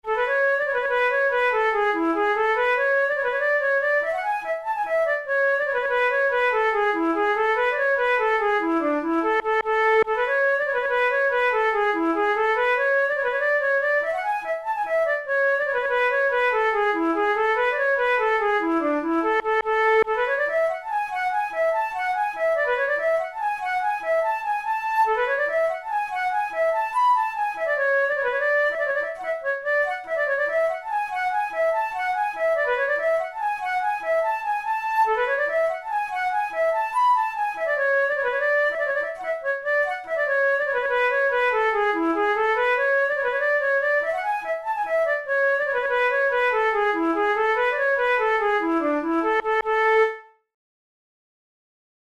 InstrumentationFlute solo
KeyA major
Time signature6/8
Tempo96 BPM
Jigs, Traditional/Folk
Traditional Irish jig